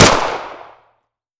Pistol_Shoot 01.wav